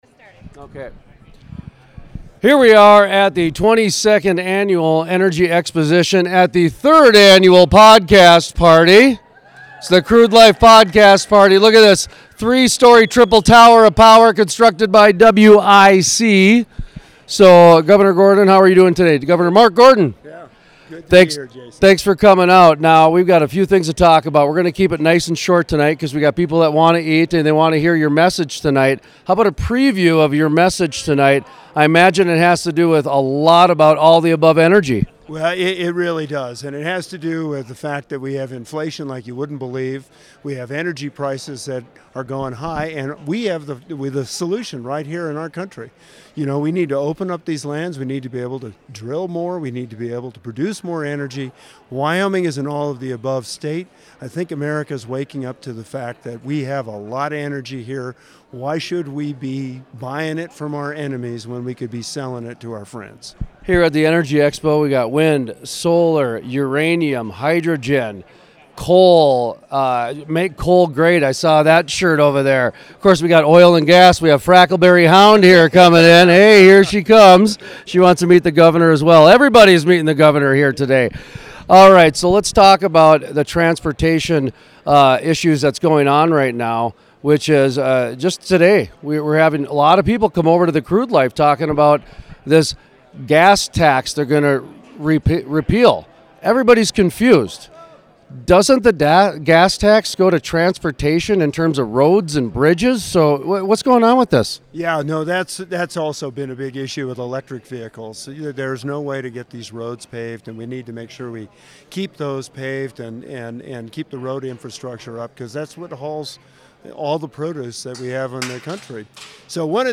Full Length Interviews
The interview was conducted from the Triple Tower of Power constructed by  WIC for The Crude Life’s 3rd Annual Podcast Party at the 22nd Annual Energy Exposition in Gillette, Wyoming.